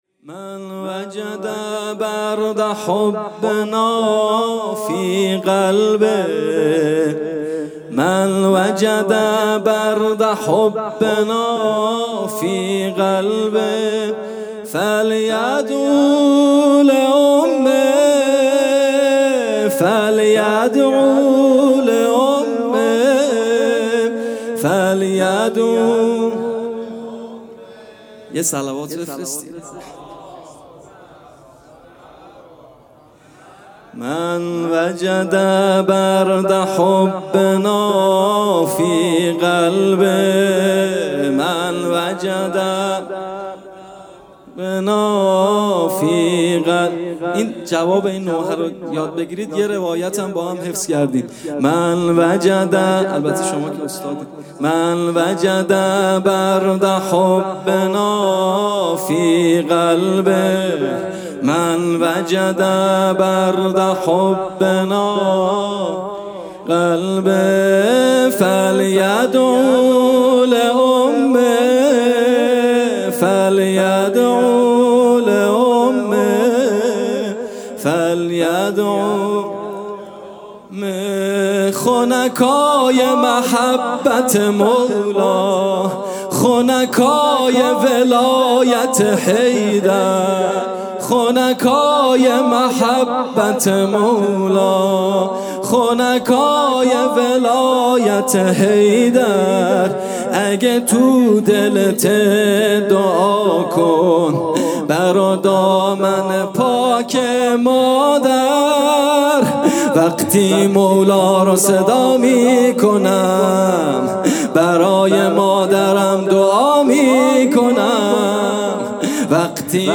جلسه هفتگی
music-icon سرود: مَن وَجَدَ بَردَ حُبّنَا في قَلْبِه...